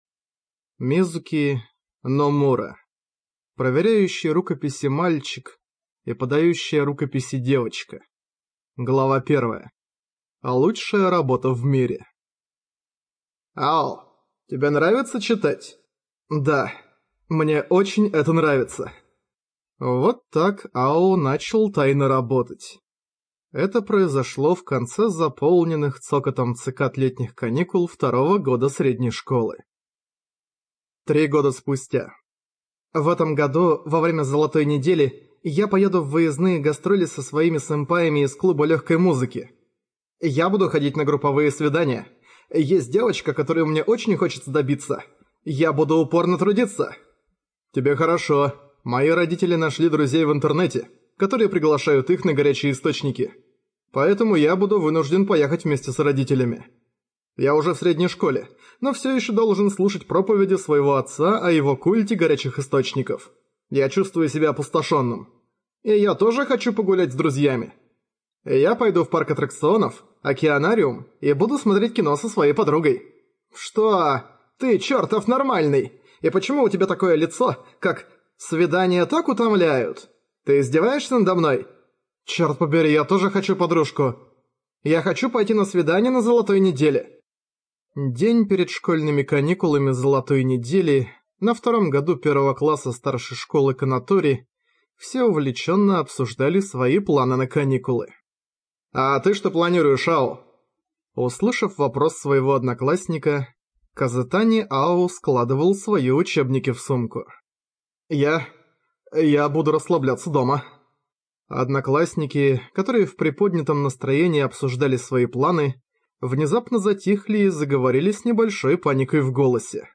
Аудиокнига из раздела "Ранобэ"